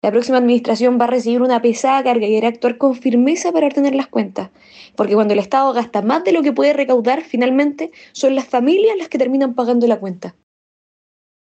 En esa línea, la diputada republicana Chiara Barchiesi sostuvo que la próxima administración enfrentará una carga fiscal “muy pesada”.